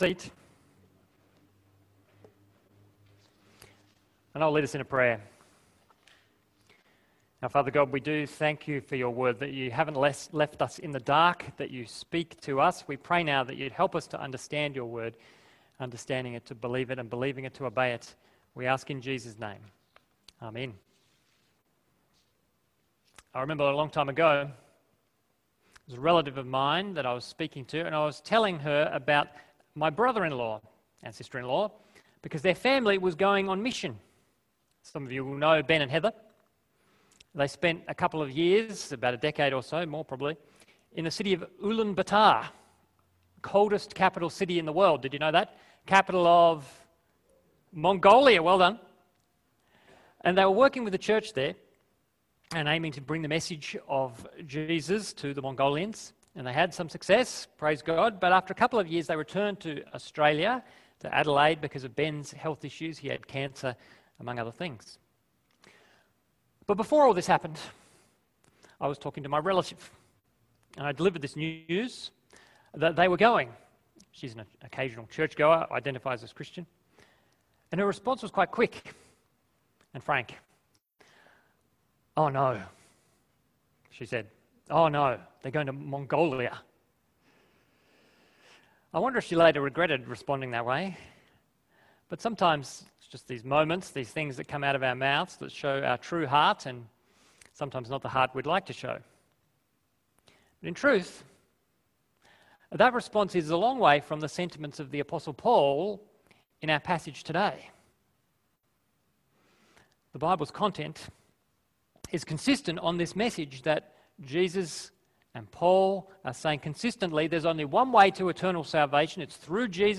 Sermons | St George's Magill Anglican Church